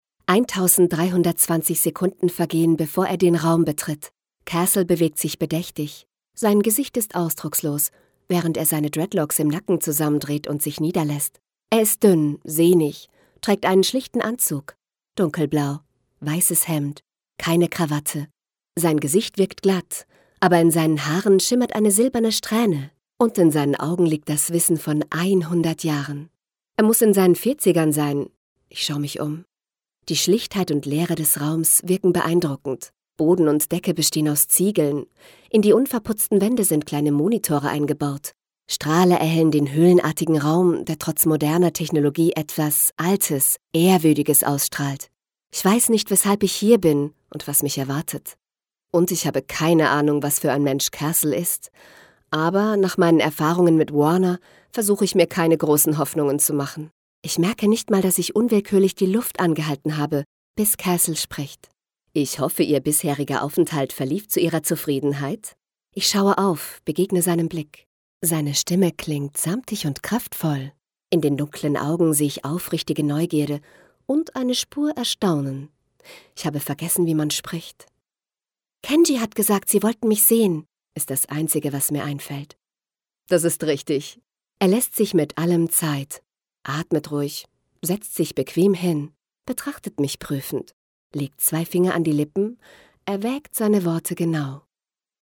Warme, freundliche mittlere Stimmlage.
Sprechprobe: Sonstiges (Muttersprache):
Warm voice, middle aged